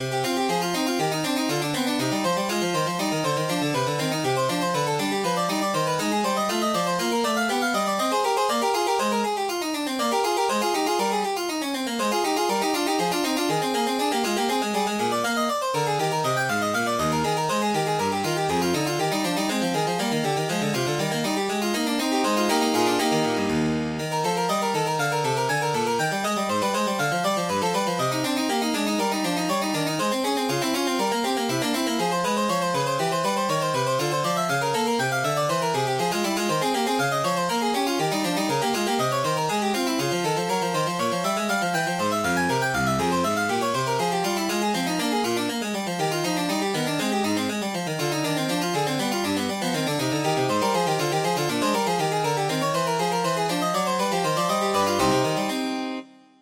Classical Bach, Johann Sebastian Praeludium II- BWV 871 Piano version
Piano Classical Piano Classical Piano Free Sheet Music Praeludium II- BWV 871